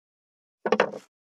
577肉切りナイフ,まな板の上,包丁,
効果音厨房/台所/レストラン/kitchen食器食材